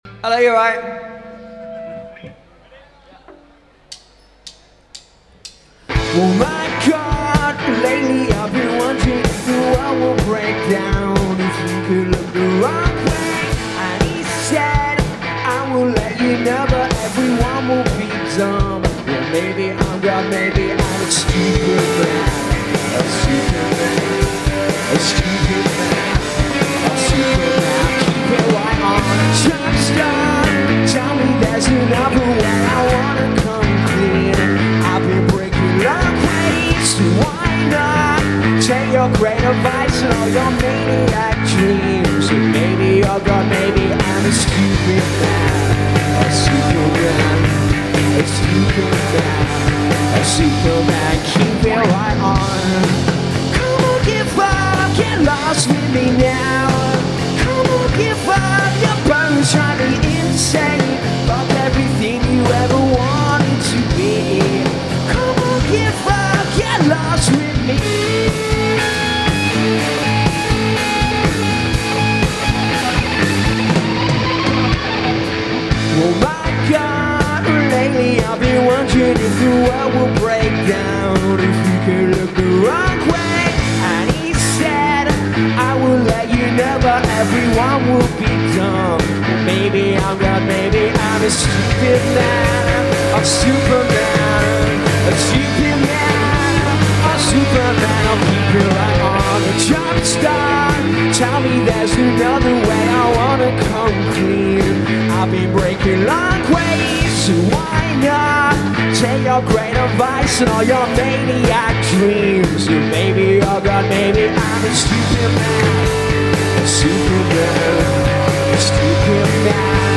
Indie/Noisepop